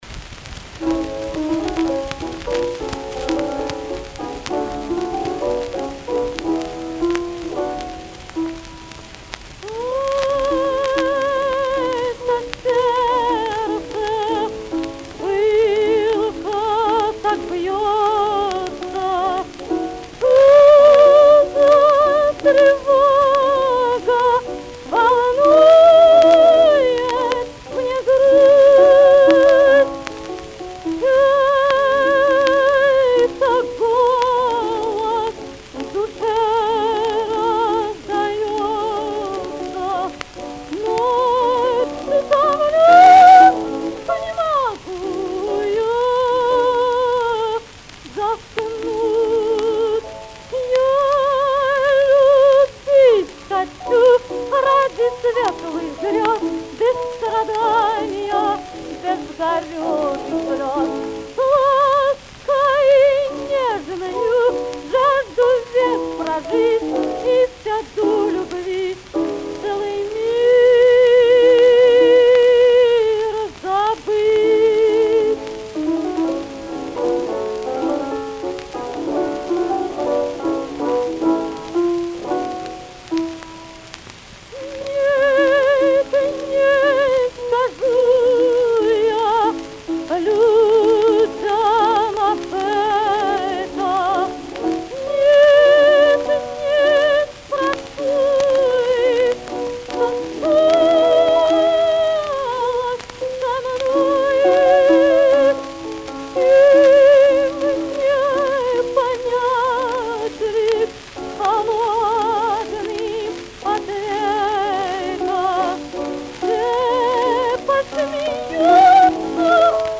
Каталожная категория: Меццо-сопрано с фортепиано |
Жанр: Цыганский романс
Вид аккомпанемента: Фортепиано
Место записи: С.-Петербург |